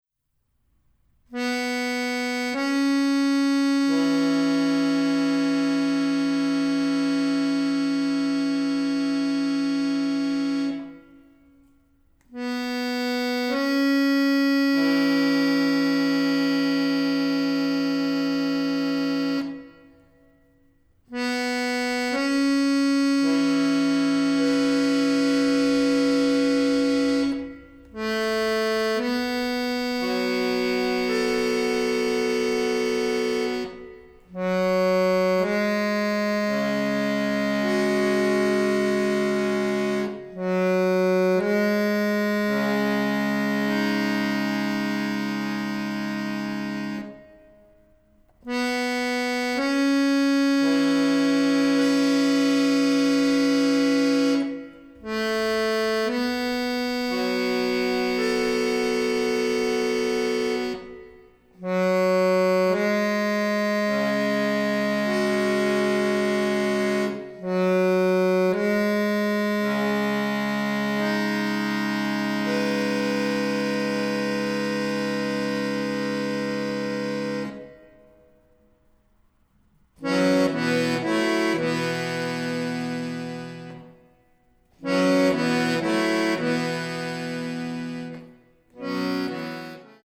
ピアニスト